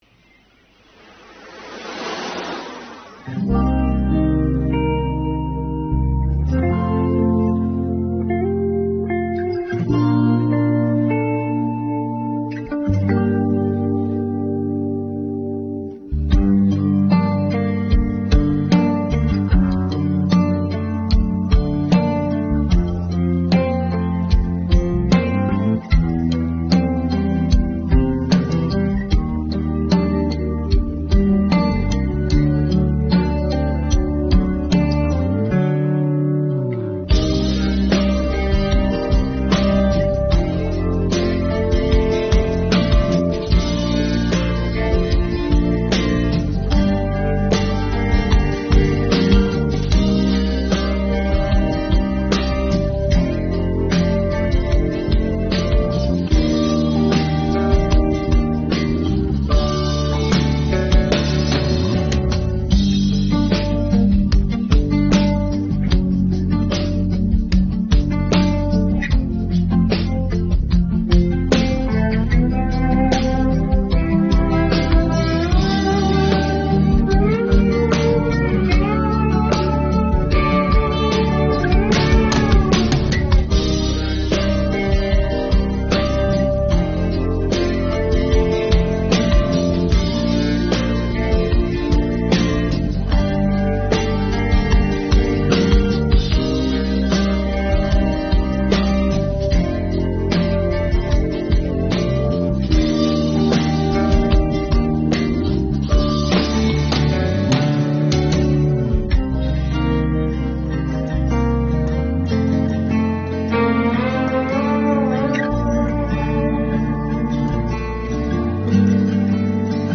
безкоштовна мінусовка для співу онлайн.
pop-folk караоке